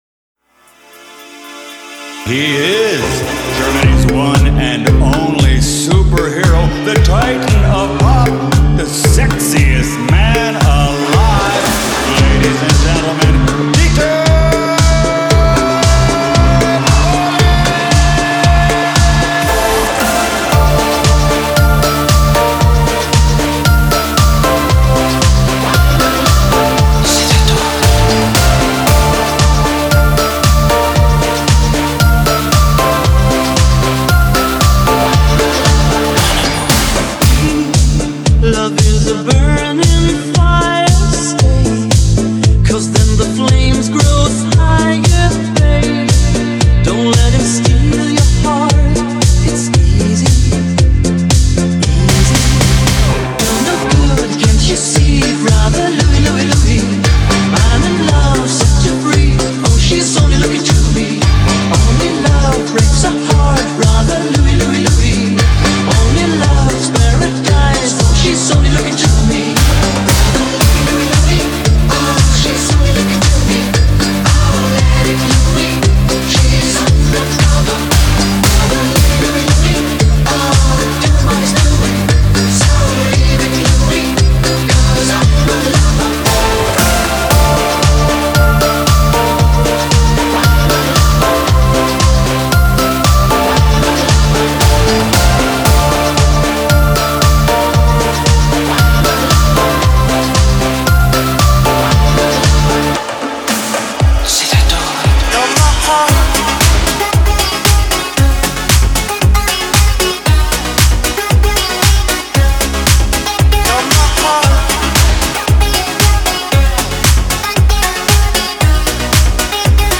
это динамичный и энергичный трек в жанре евро-диско